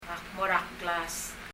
上の例では、 ・・mo er a・・　は[mɔ　ɛr ə]ではなく、[mɔrə]　になります。
er の e は音としては消えます。
発音